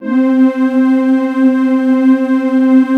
14PAD 01  -R.wav